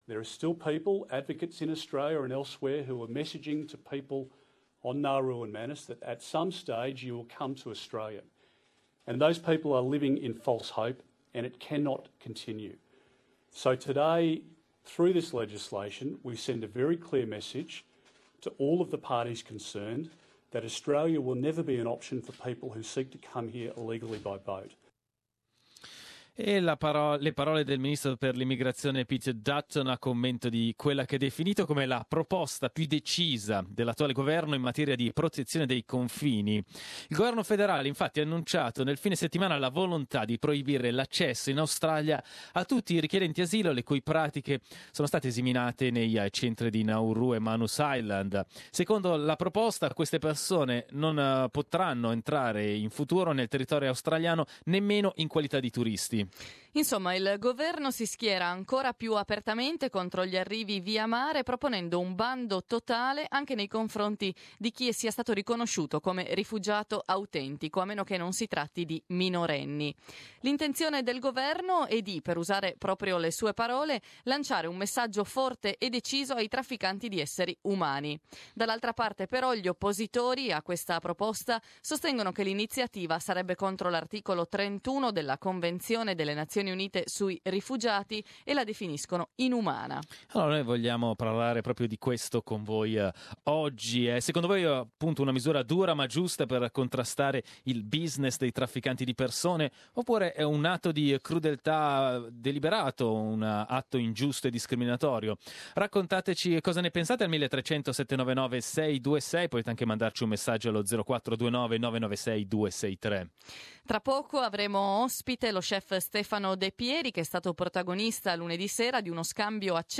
We have asked our audience what they think of the government proposal to stop asylum seekers who arrived by boat from ever entering Australia. We opened the debate interviewing